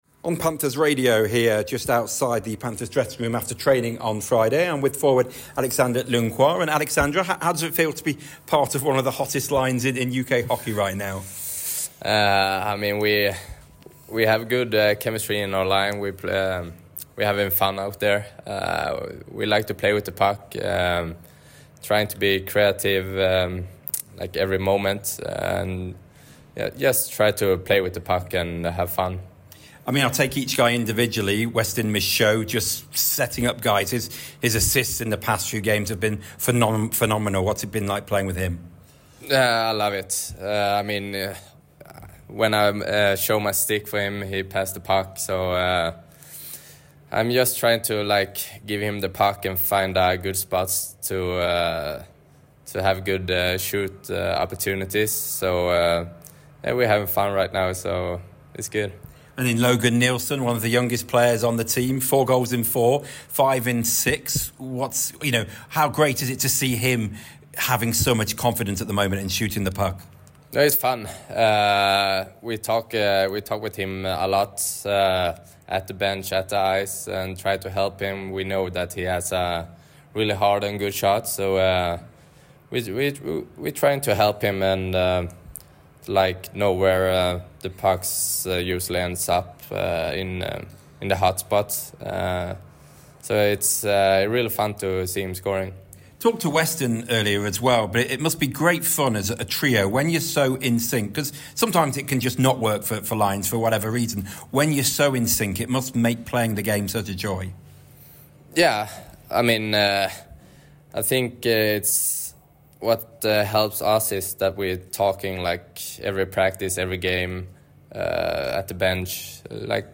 interview on Panthers Radio